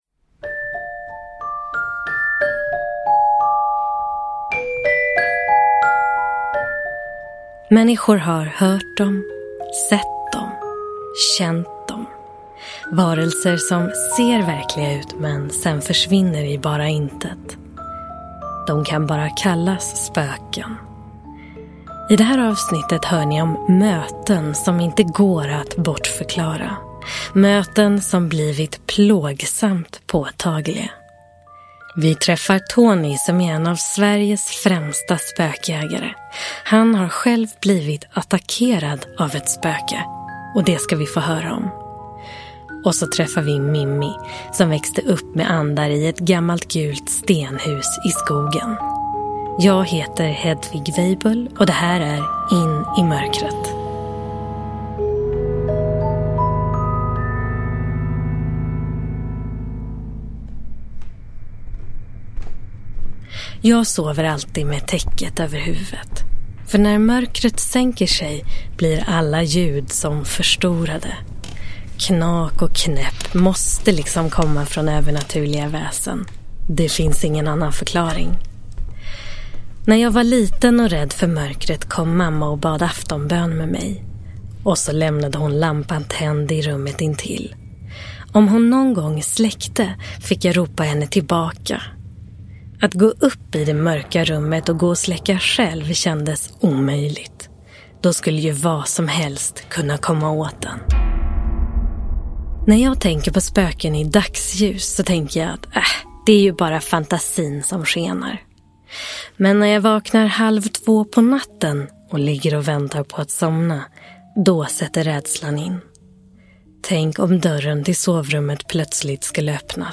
Hör deras egna berättelser.